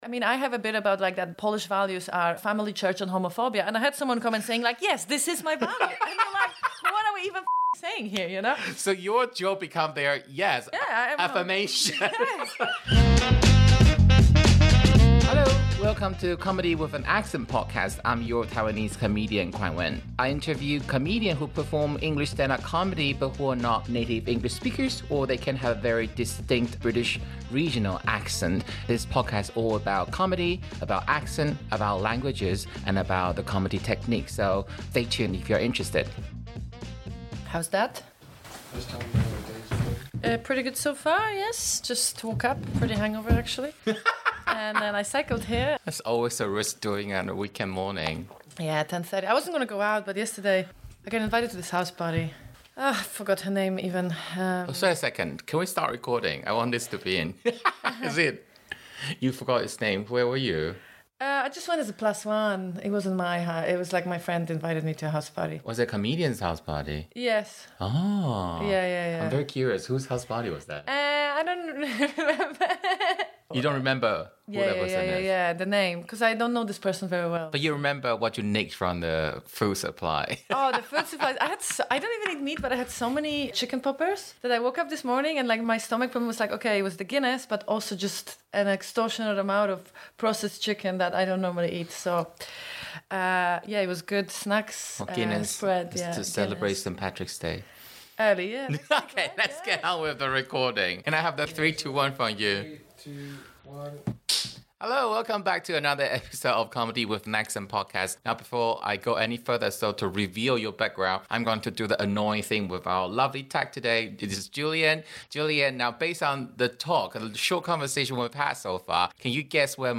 Yet her accent morphs where she moves to, so she ends up with a funny scenario of a faint German accent even if she does not speak fluent German.